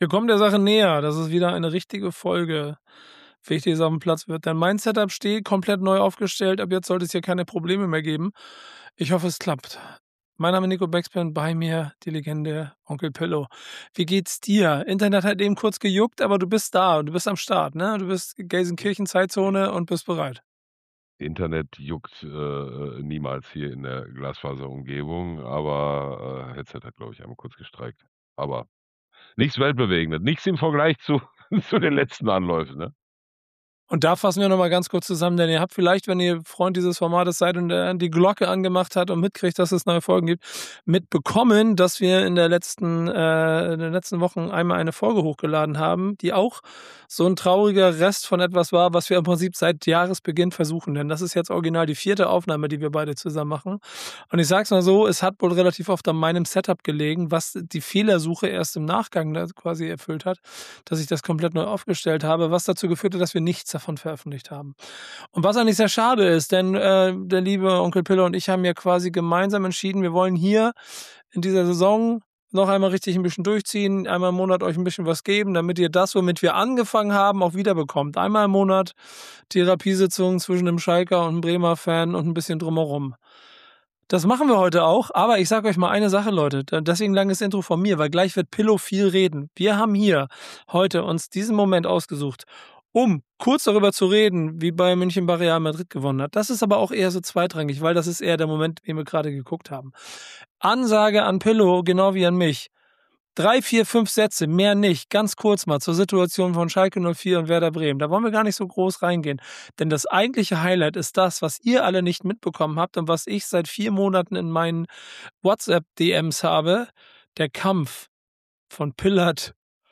Vierte Aufnahme, neues Setup, endlich wieder Therapiesitzung.